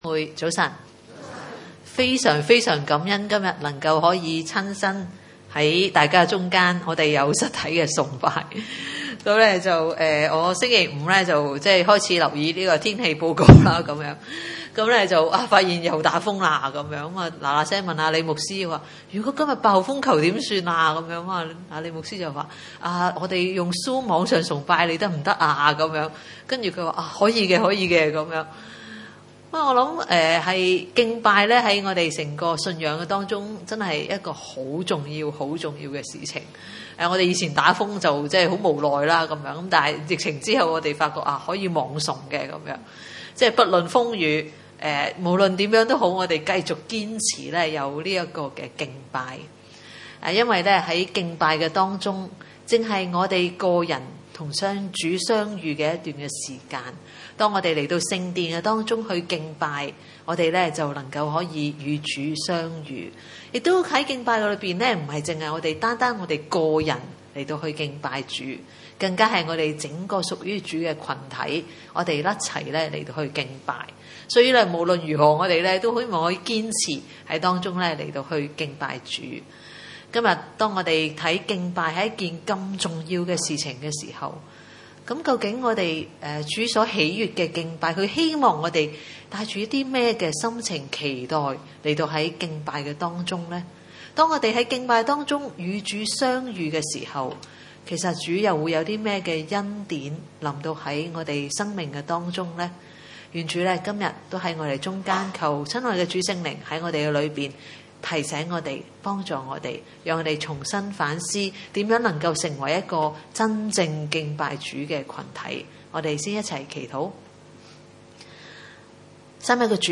崇拜類別: 主日午堂崇拜 21.